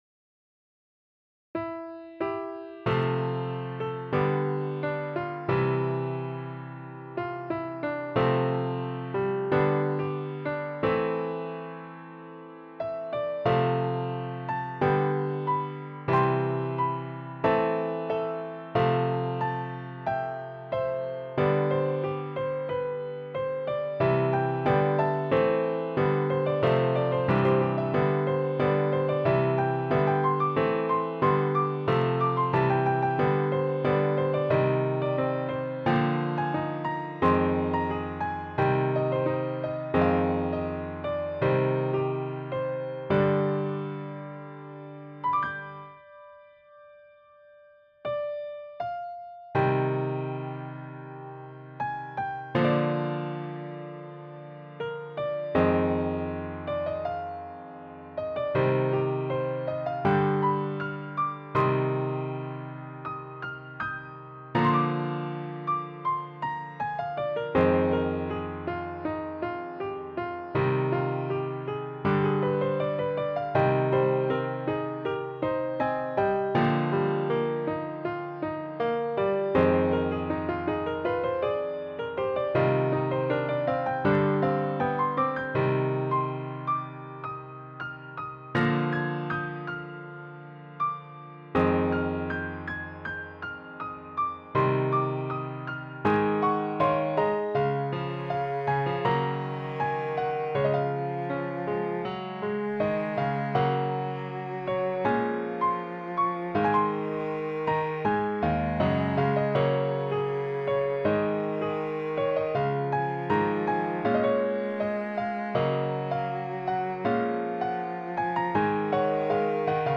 Ezen okok hatására kezdtem el saját zongoradallamokat írni, amikből ide csatolok egy párat